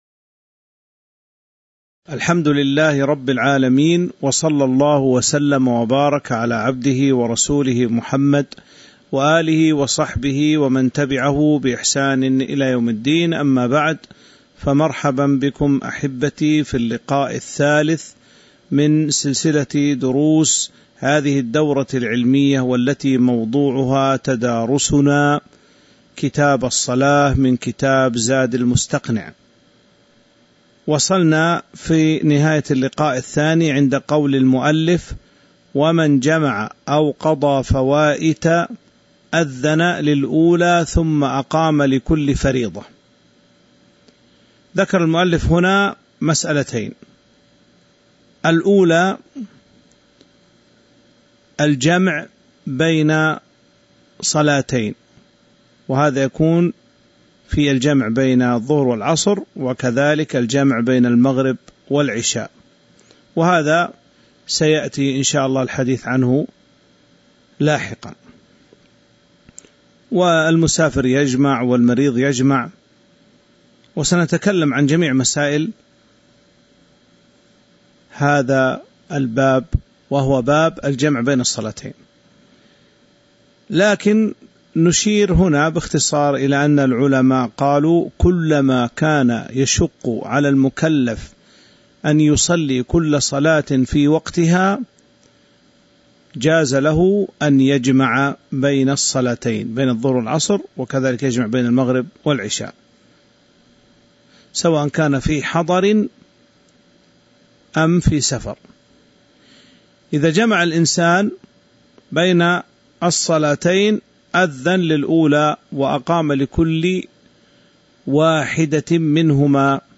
تاريخ النشر ٢٢ ذو الحجة ١٤٤٢ هـ المكان: المسجد النبوي الشيخ